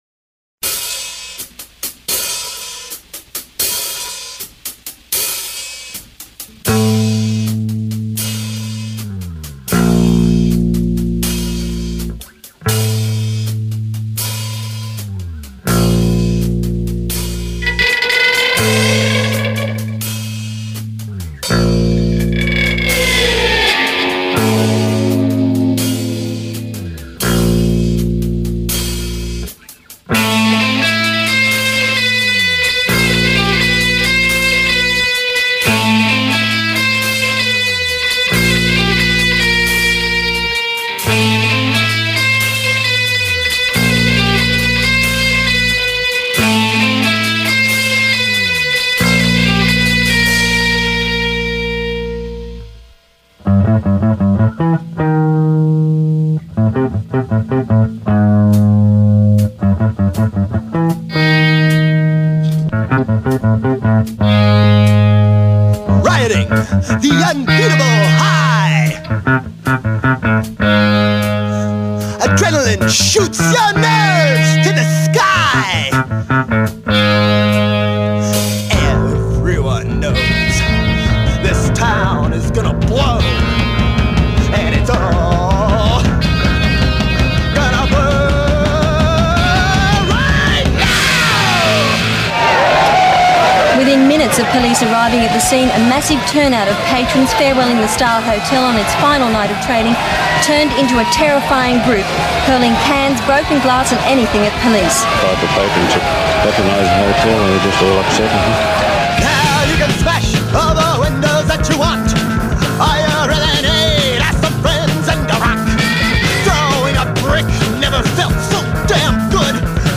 You can hear the Riot FM Opening Production, featuring news reports from the Star riot, along with cuts from the Dead Kennedy's "Riot", and then the first full song "The Star and The Slaughter" from Heroes, by clicking the "Play" icon.